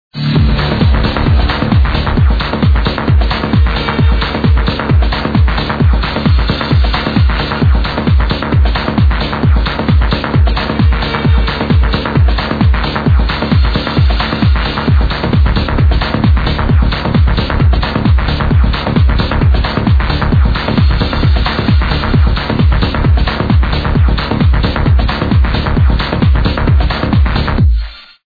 massive progressive hard energizing house track